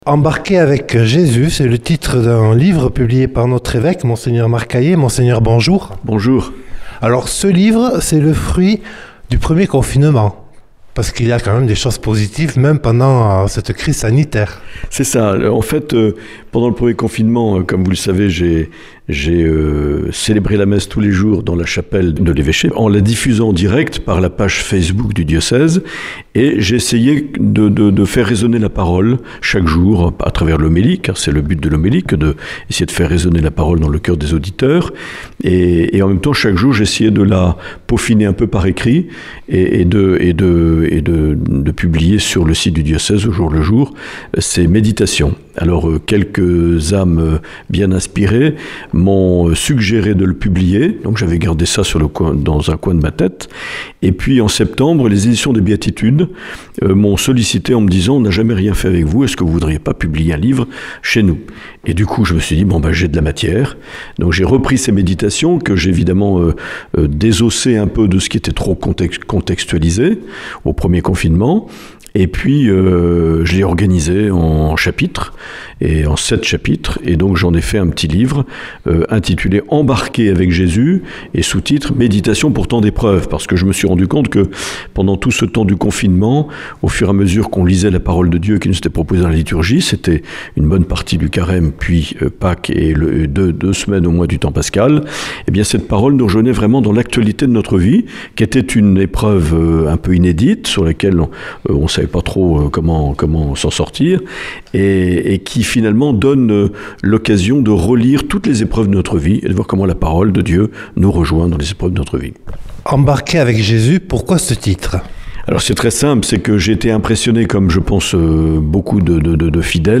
Dans cet entretien, Mgr Aillet revient sur le lancement diocésain le 21 mars à la cathédrale Sainte-Marie de Bayonne de l’Année de la famille Amoris Laetitia dans le cadre de l’Année saint Joseph.